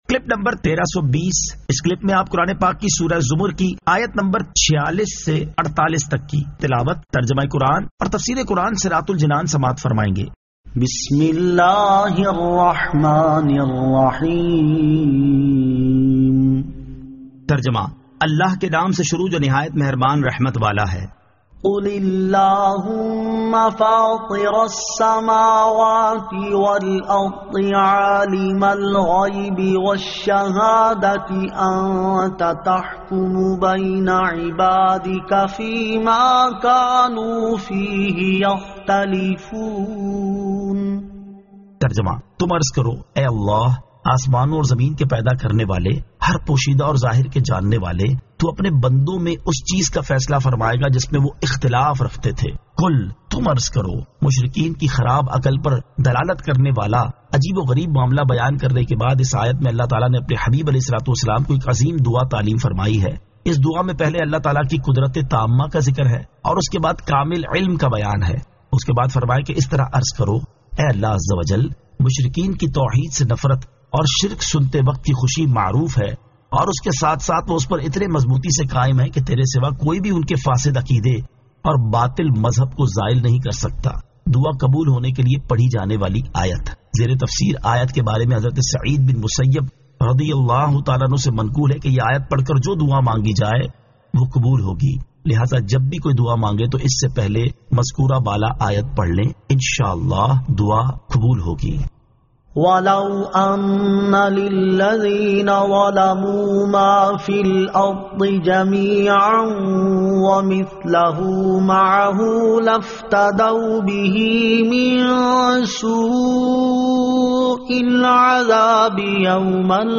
Surah Az-Zamar 46 To 48 Tilawat , Tarjama , Tafseer